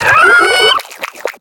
Cri de Tritosor dans Pokémon X et Y.